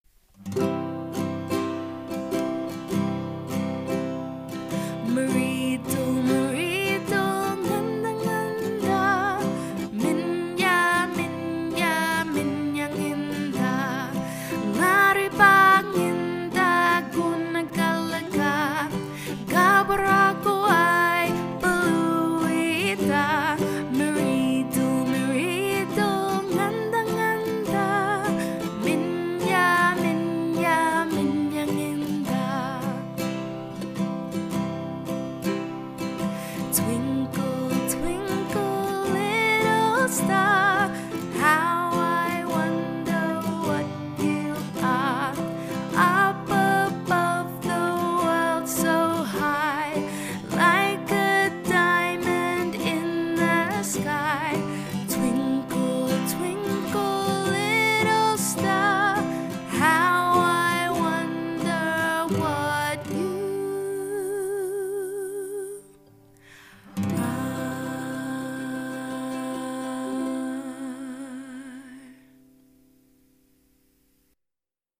This is a simple early childhood song, with some actions to make it fun for children: